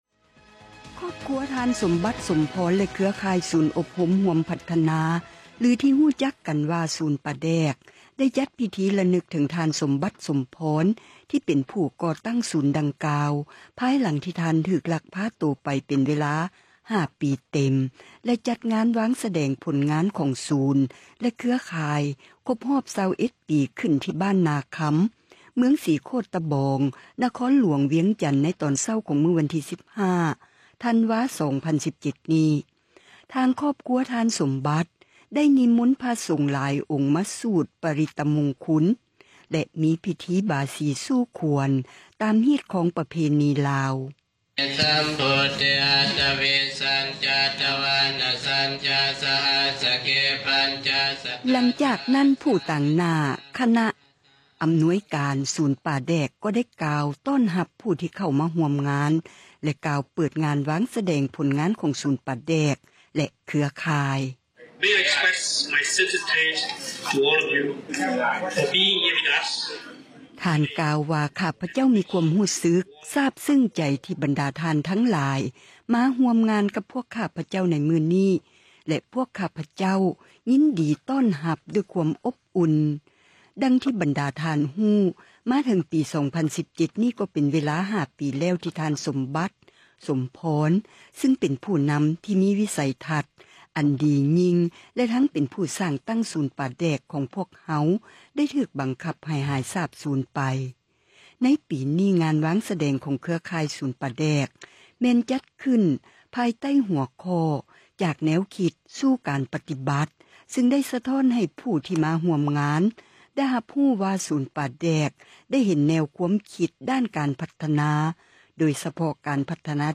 "ສຽງຄູບາເທສນາ."
ຫລັງຈາກນັ້ນ ຕາງໜ້າຄະນະອຳນວຍການສູນປາແດກ ກໍໄດ້ກ່າວຕ້ອນຮັບຜູ້ທີ່ເຂົ້າມາຮ່ວມງານ ແລະກ່າວເປີດງານວາງສະແດງຜົລງານ ຂອງສູນປາແດກ ແລະເຄືອຂ່າຍ: